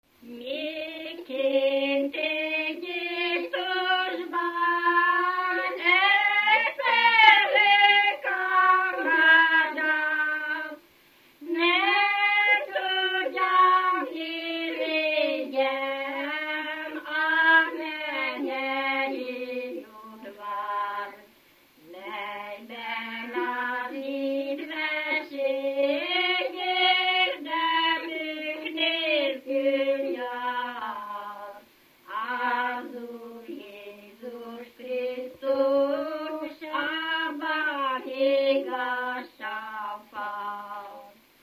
Dunántúl - Verőce vm. - Haraszti
Stílus: 4. Sirató stílusú dallamok
Kadencia: 5 (1) b3 1